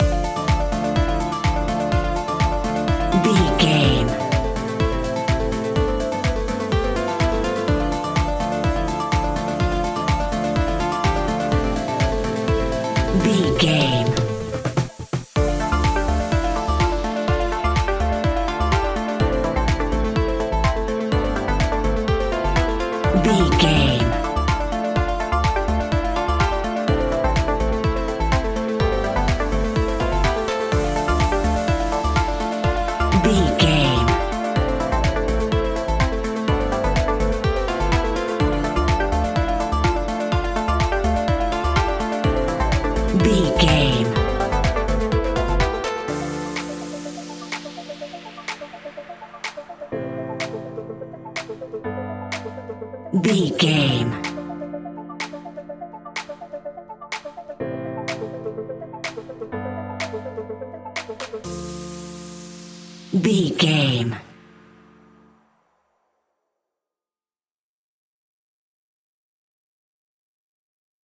Aeolian/Minor
D
energetic
uplifting
hypnotic
funky
drum machine
synthesiser
electro house
synth leads
synth bass